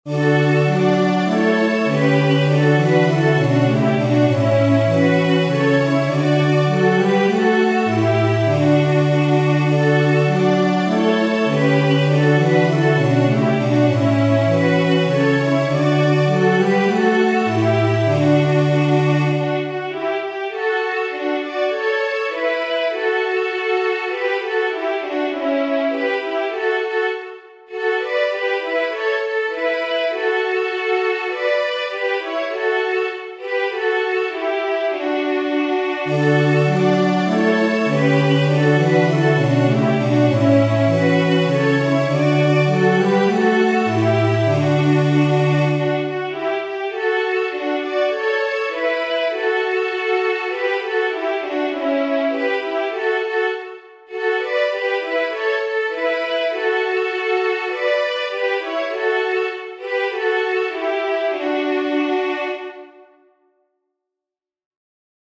• Catégorie : Chants d’Acclamations.